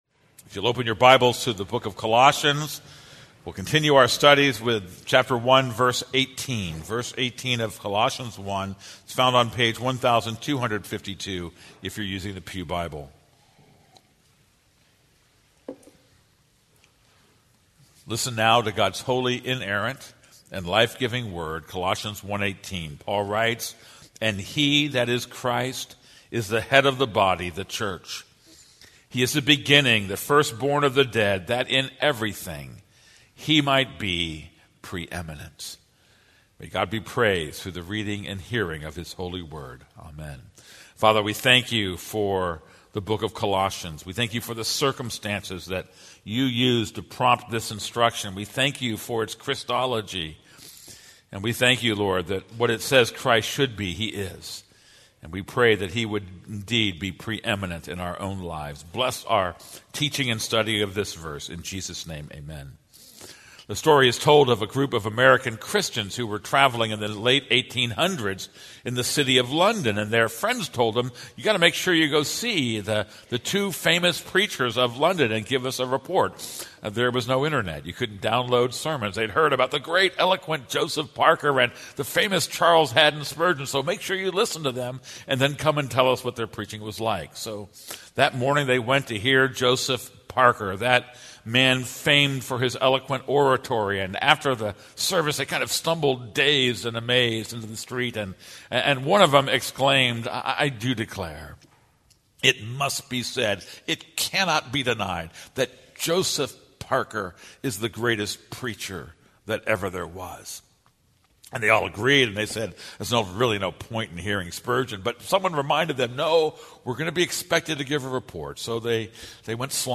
This is a sermon on Colossians 1:18.